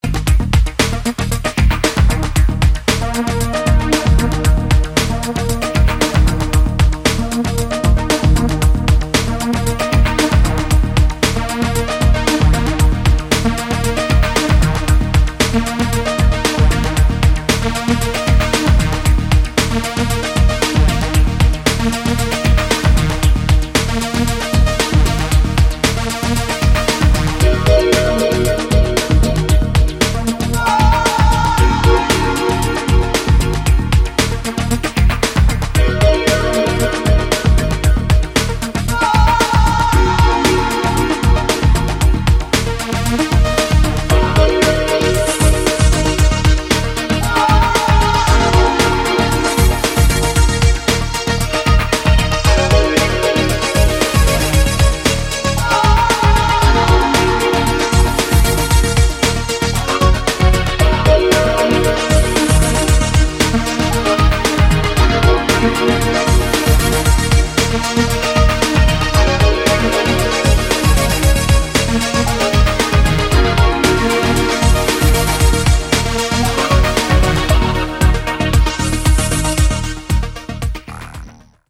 グッとテンポを落とし、極彩色でコズミックなシンセワークが空間性を覆うアシッド・ブレイクビーツ路線の推薦曲です！